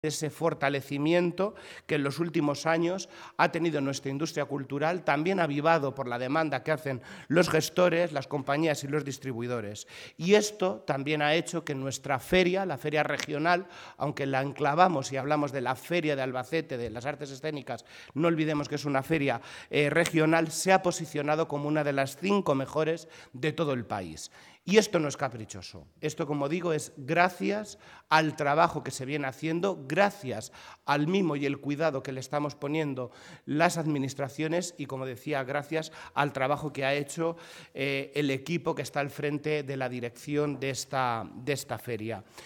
Lo ha avanzado el consejero de Educación, Cultura y Deportes, Amador Pastor, en la rueda de prensa de presentación de la muestra, celebrada en la delegación de la Junta de Albacete.